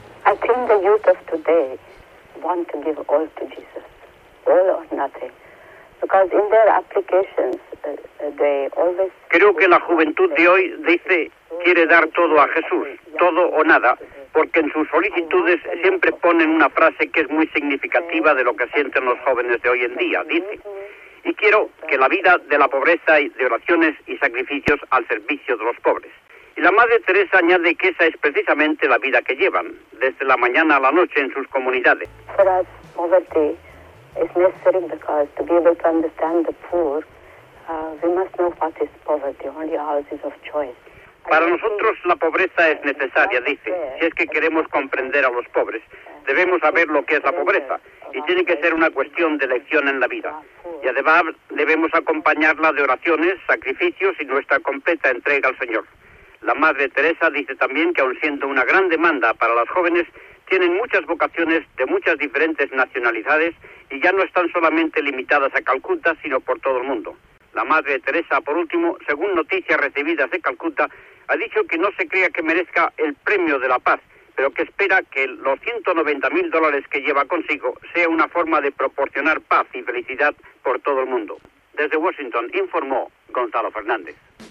Entrevista telefònica a la monja mare Teresa de Calcuta, que havia rebut el premi Nobel de la Pau, mentre estava de visita a Washington (EE.UU.)
Informatiu